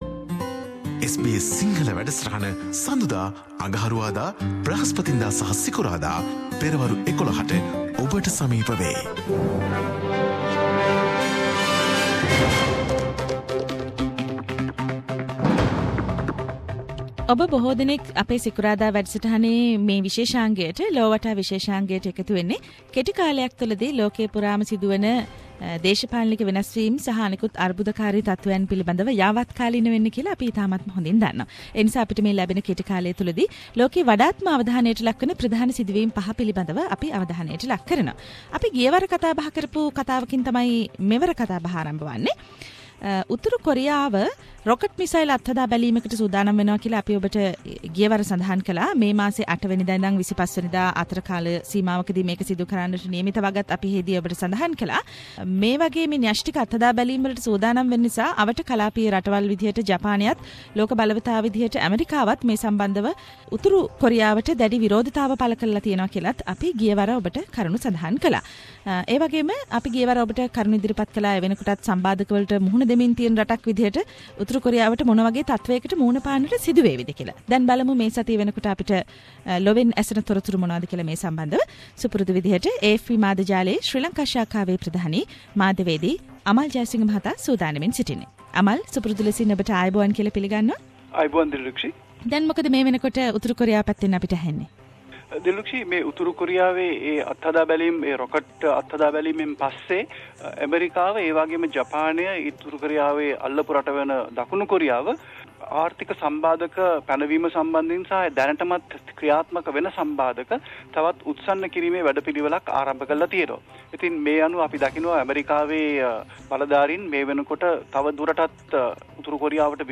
Weekly world news wrap